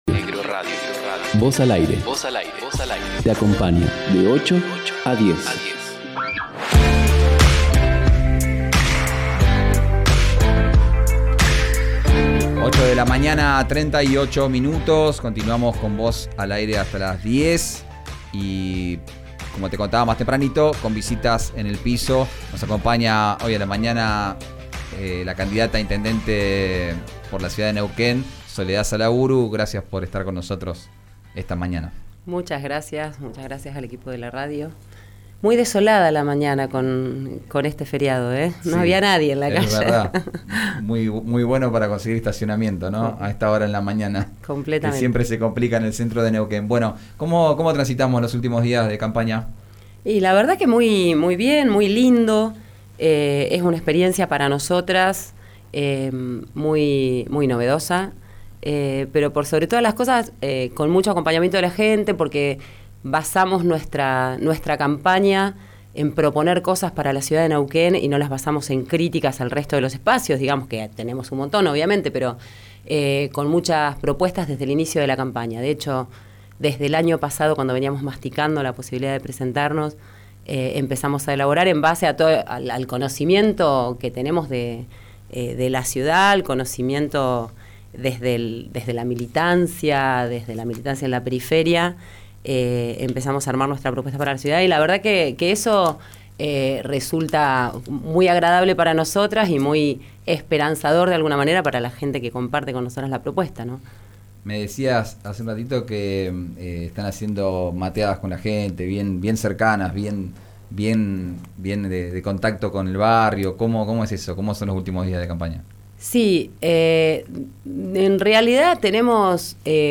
Escuchá la entrevista completa en 'Vos al Aire'.